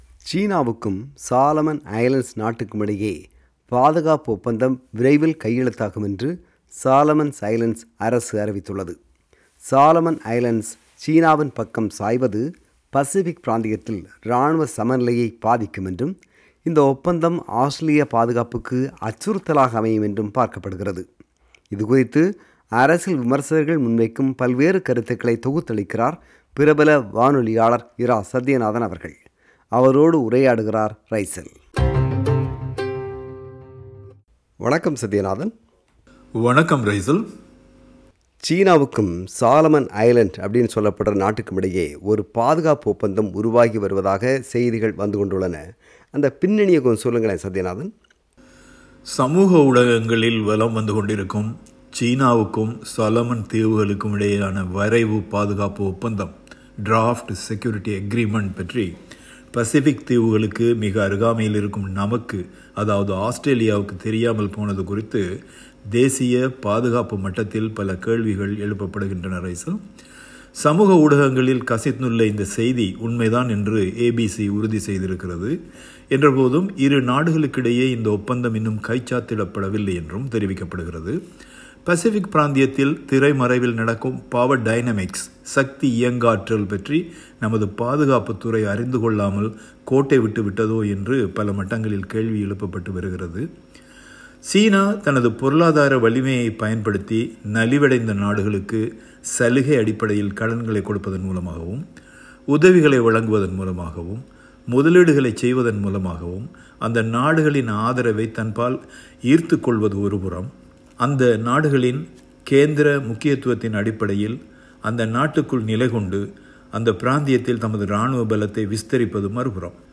a veteran broadcaster, explains the story.